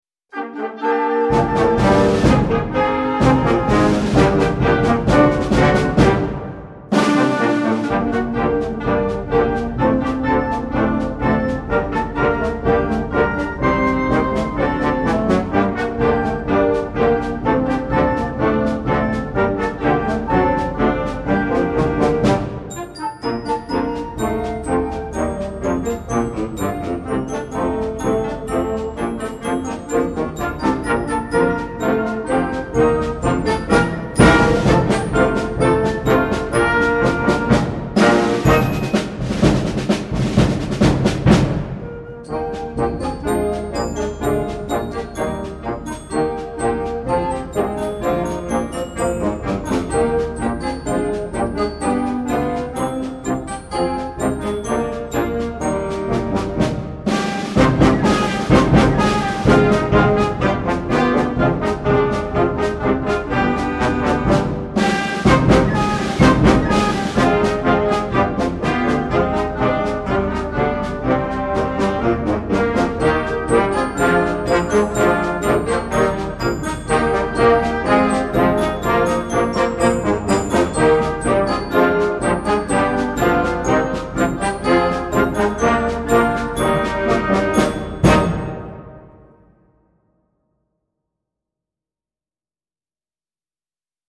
Concert Band
It begins in G minor but transitions to Eb major.